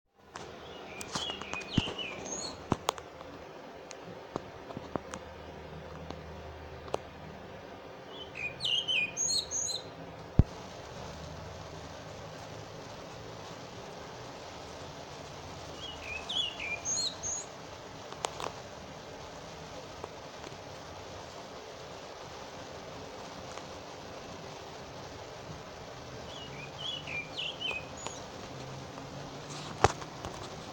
Zorzal Chiguanco (Turdus chiguanco) - EcoRegistros
Nombre en inglés: Chiguanco Thrush
Fase de la vida: Adulto
Condición: Silvestre
Certeza: Observada, Vocalización Grabada